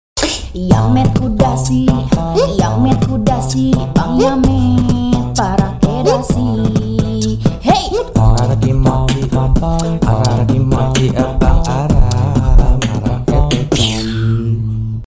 ngakak sound effects free download